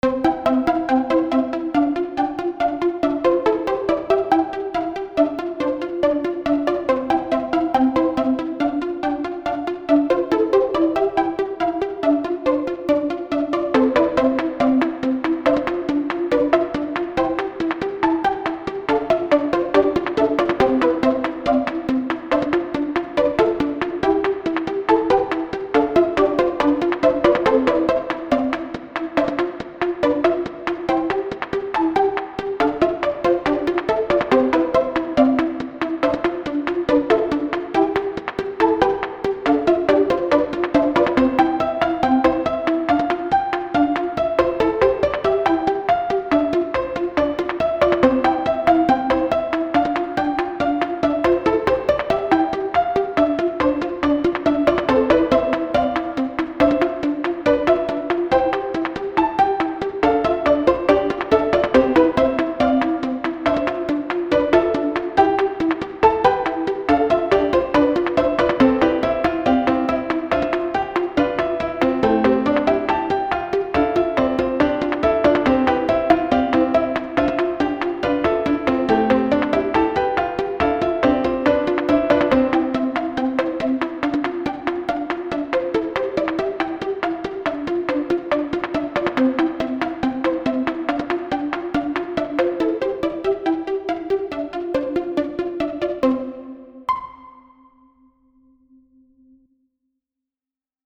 You can use this for bgs in funny chaotic games or moments!
Tags: goofy funny